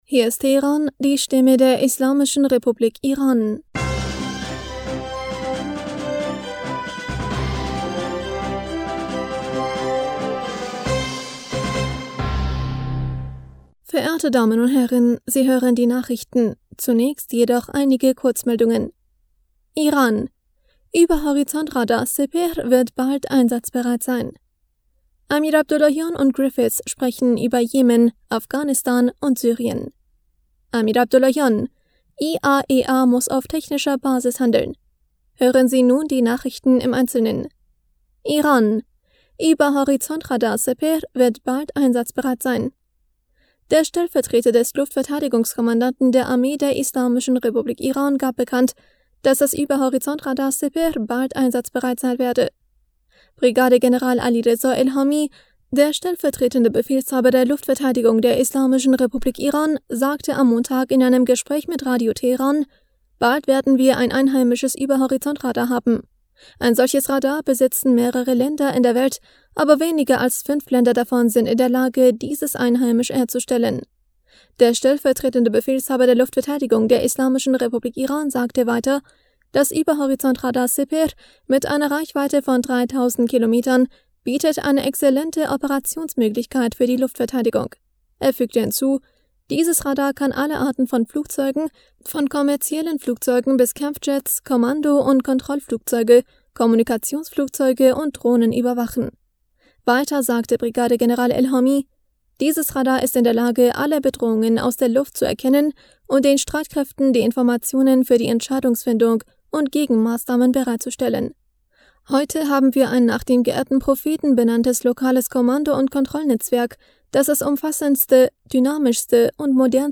Nachrichten vom 27. September 2022
Die Nachrichten von Dienstag, dem 27. September 2022